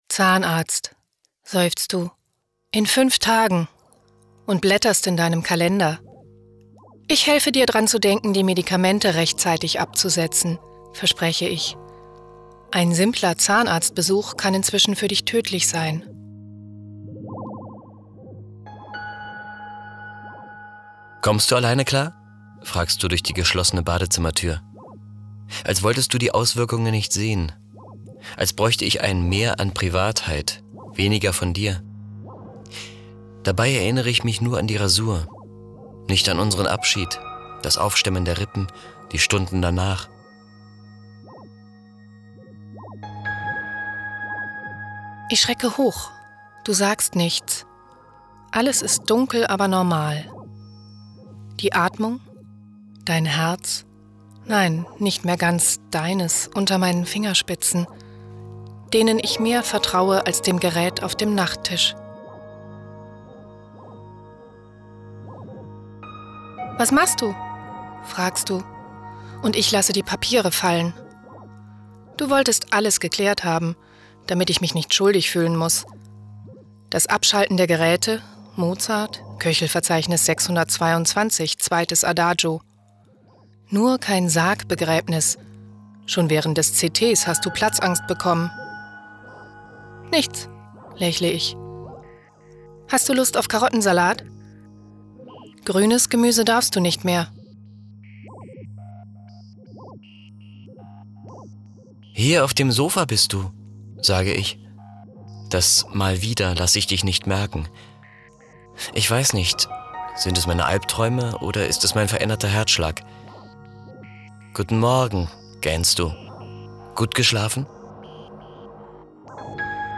Ein Tod womöglich - Hörstück